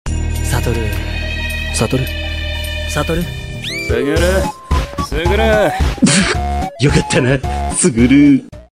Geto sound so soft and warm while gojo always sound like he is mocking geto 😭 (but we know he can say “suguru” in a soft warm voice 🥹)